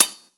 surface_metal5.mp3